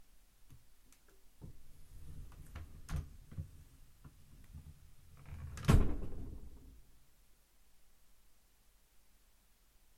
Slow/Close Top Drawer 5
Duration - 9 sEnvironment - Bedroom, absorption of curtains, carpet and bed. Description -Close, pull, slowly wooden drawer, shuts with bang.